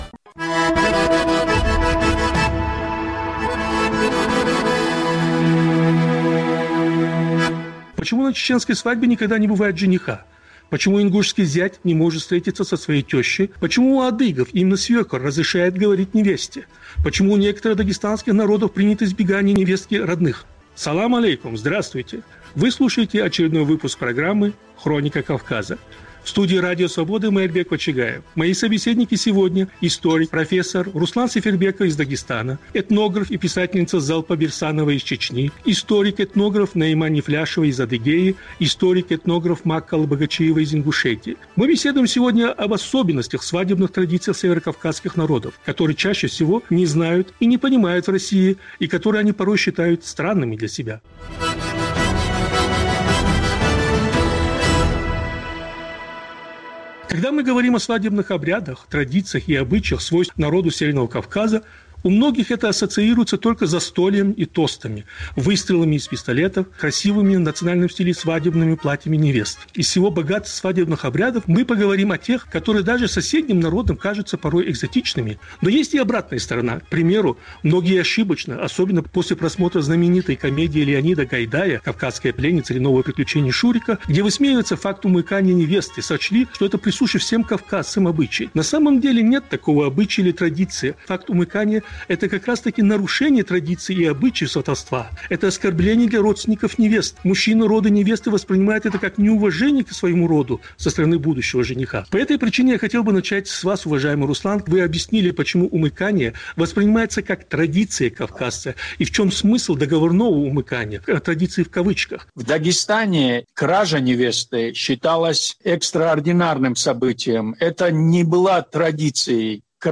Повтор программы от 25.04.2021 года.